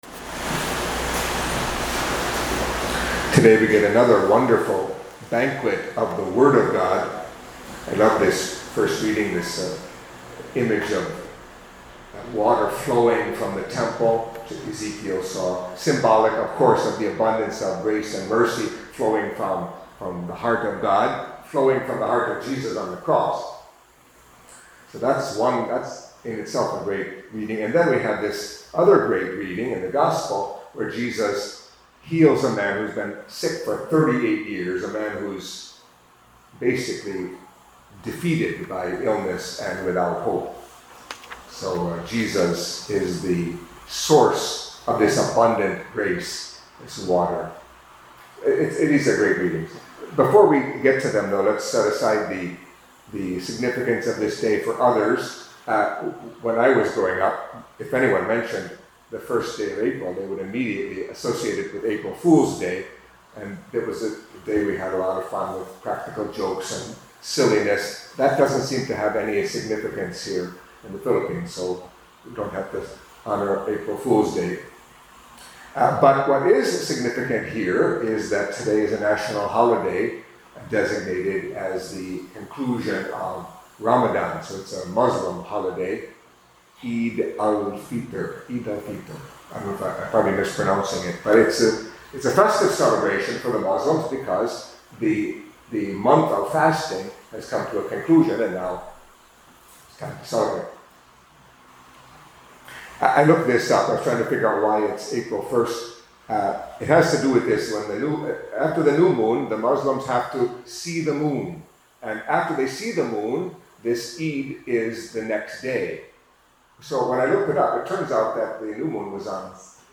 Catholic Mass homily for Tuesday of the Fourth Week of Lent